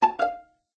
musical_notes_2.ogg